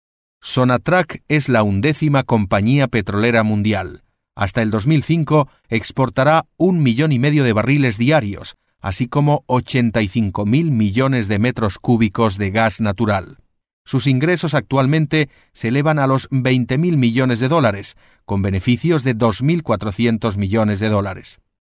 Sprechprobe: eLearning (Muttersprache):
spanish voice over artist